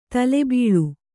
♪ tale bīḷu